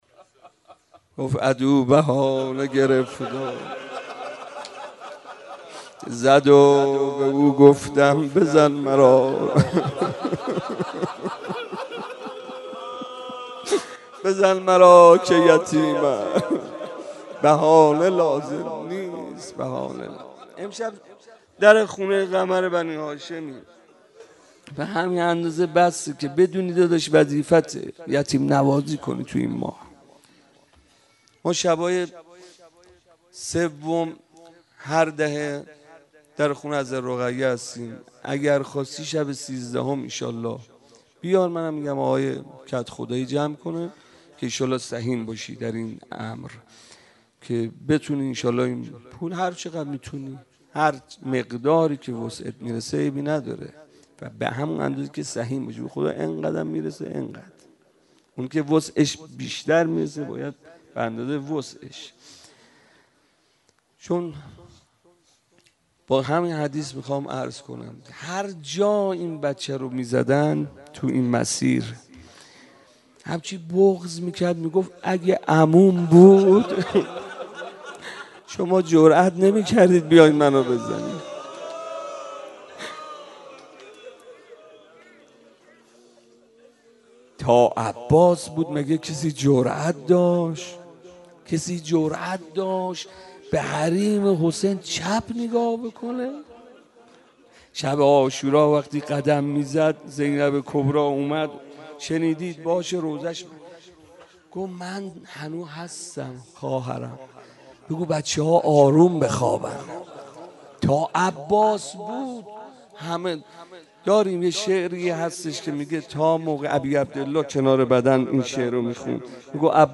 روضه
02.rozeh.mp3